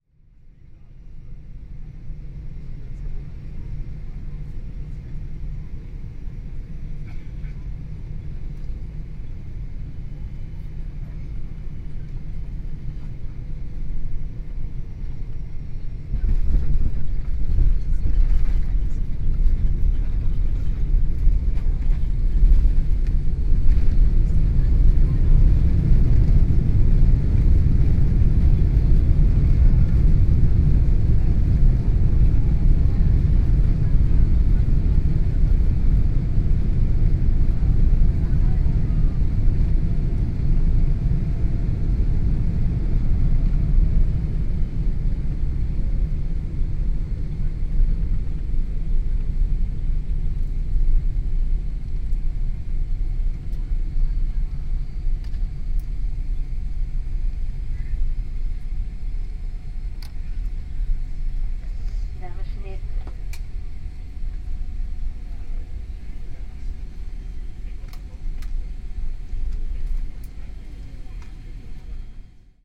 Звук посадки самолета изнутри салона